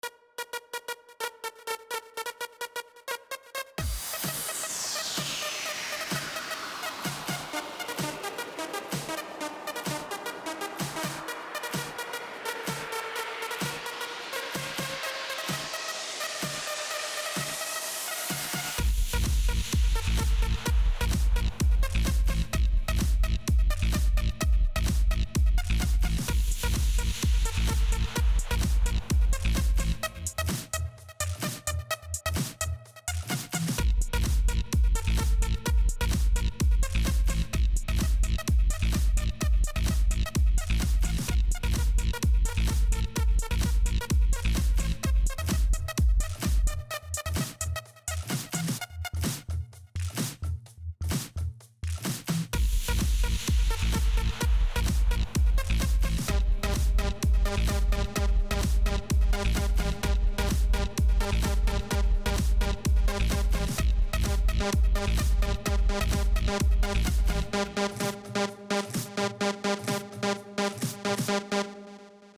Скачать Минус
Стиль: Electronic